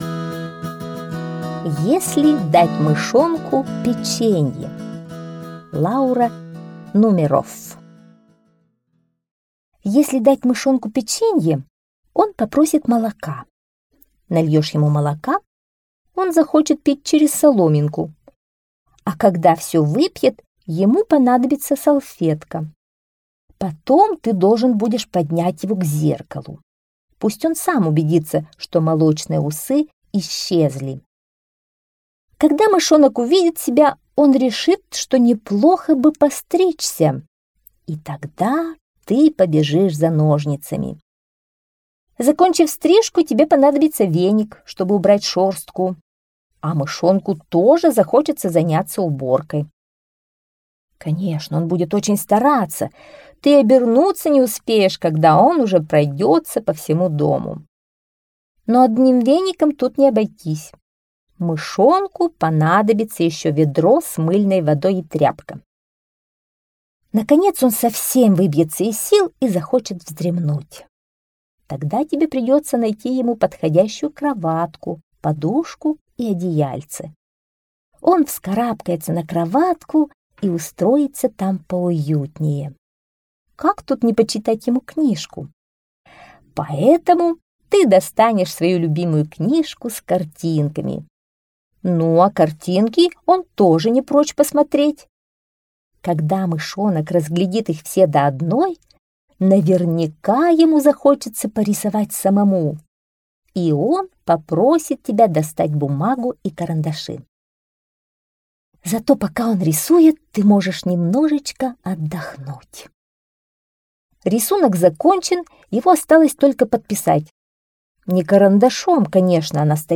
Если дать мышонку печенье - аудиосказка Нумерофф